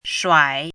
怎么读
shuǎi